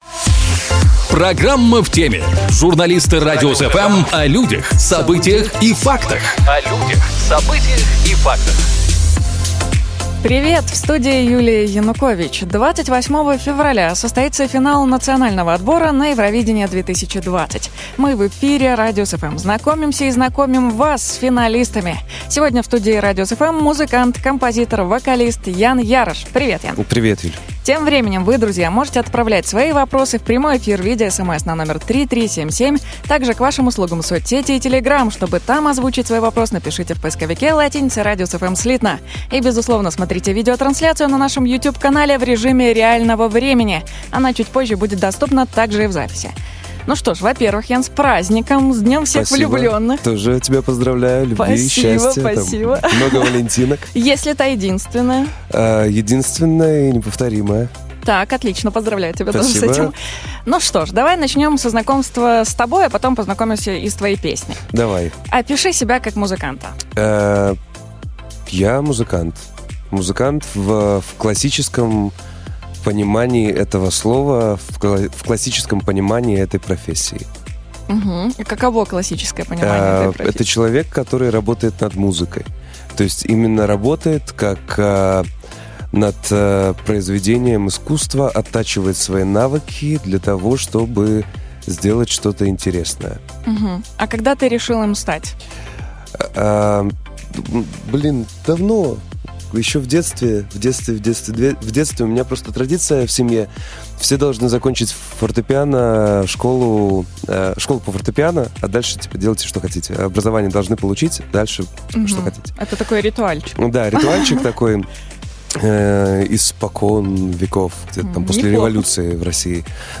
28 февраля состоится Финал Национального отбора на Евровидение-2020. Мы в эфире "Радиус FМ" знакомимся с финалистами.